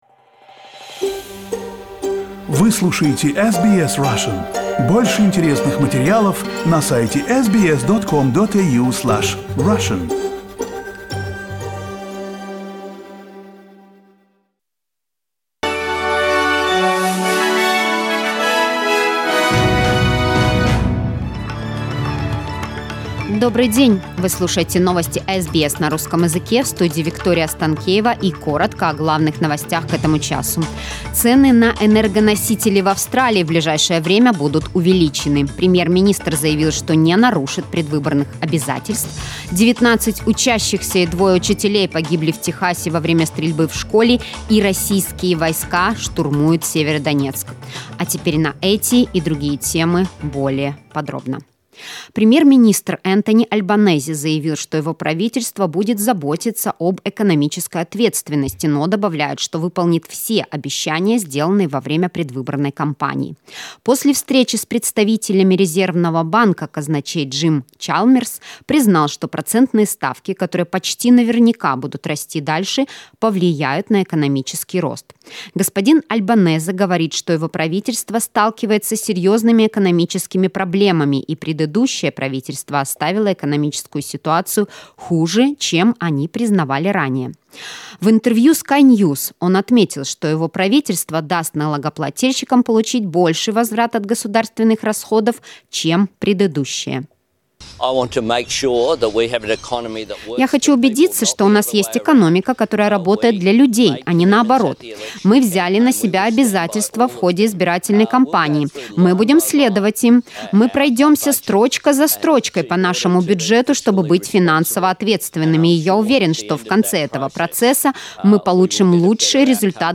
SBS news in Russian - 26.05.2022